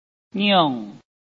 拼音查詢：【海陸腔】ngiung ~請點選不同聲調拼音聽聽看!(例字漢字部分屬參考性質)